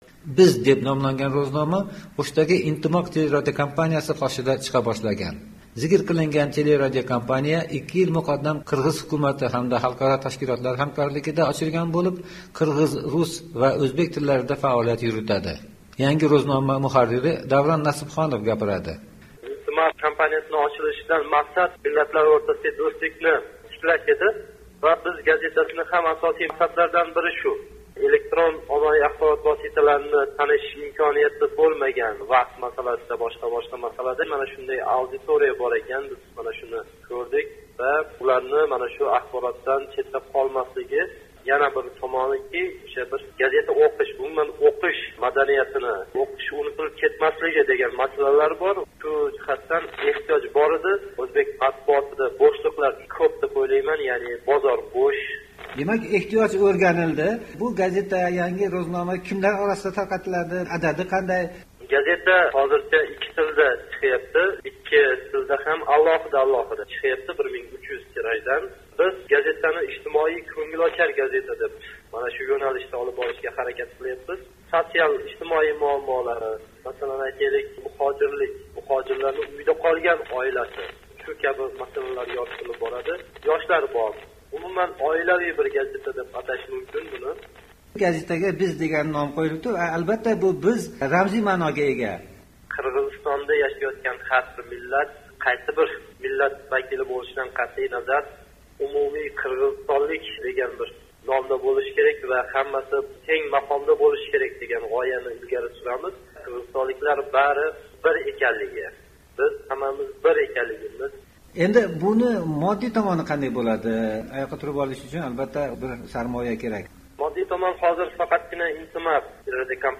Tafsilotlar O'shdan uzatilgan reportajimizda: